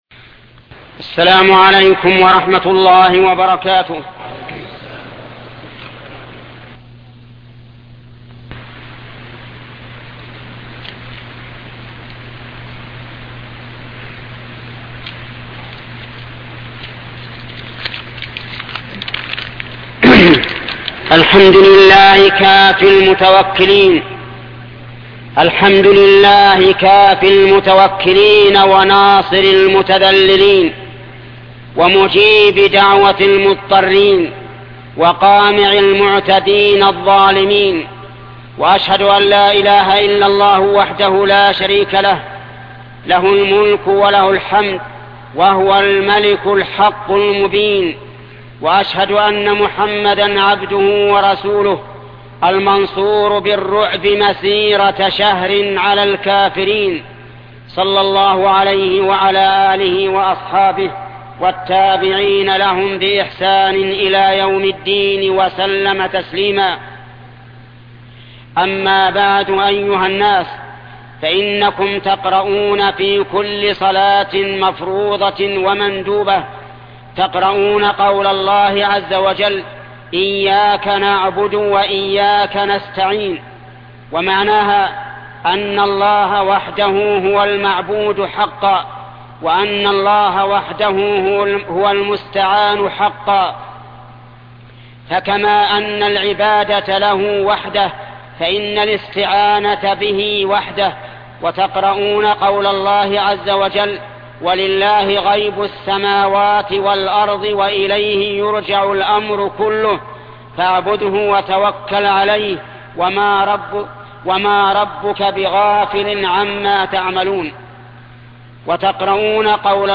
خطبة أنقذوا أنفسكم الشيخ محمد بن صالح العثيمين